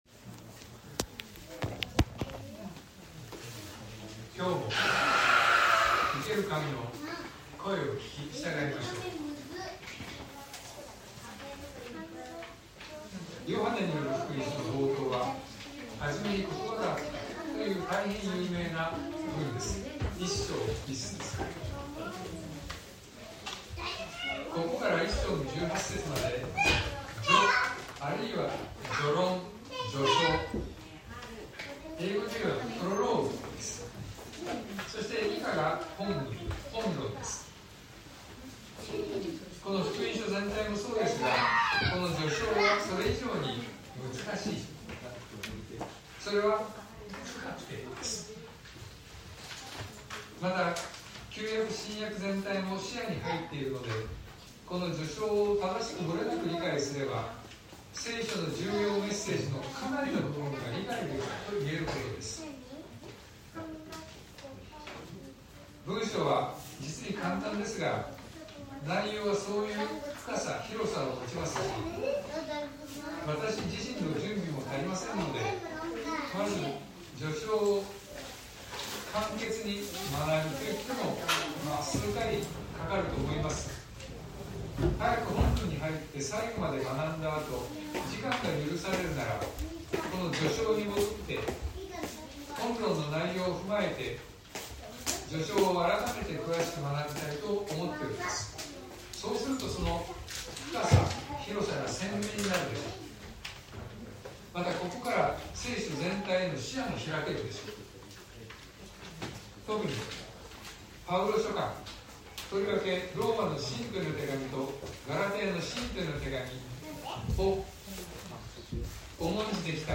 東京教会。説教アーカイブ。